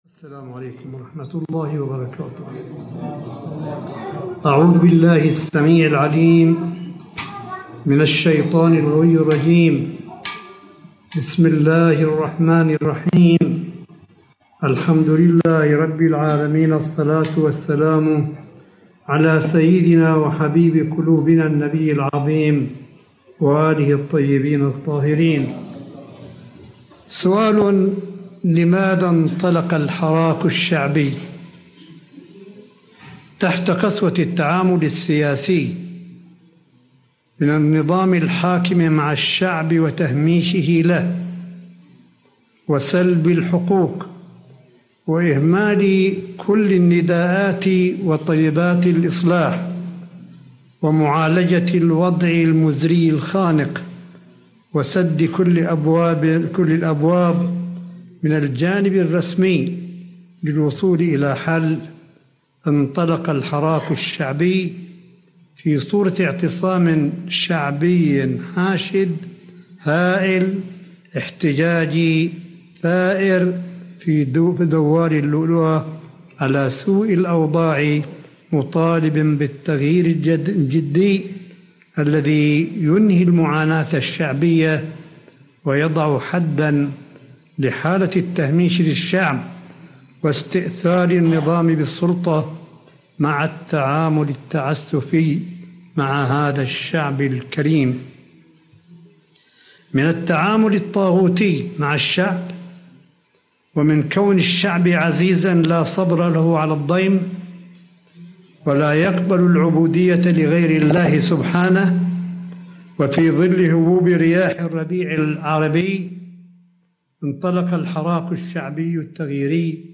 ملف صوتي لكلمة سماحة آية الله الشيخ عيسى أحمد قاسم بمناسبة ذكرى انطلاق ثورة ١٤ فبراير البحرانية سنة ٢٠١١ – بمجمع الإمام الصادق (ع) في مدينة قم المقدسة ١٣ فبراير ٢٠٢١م